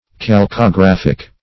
Search Result for " calcographic" : The Collaborative International Dictionary of English v.0.48: Calcographic \Cal`co*graph"ic\, Calcographical \Cal`co*graph"ic*al\, a. Relating to, or in the style of, calcography.
calcographic.mp3